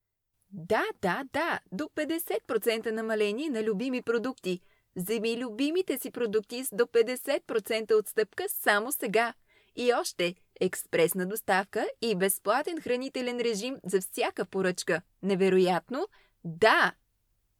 商业广告【自然优雅】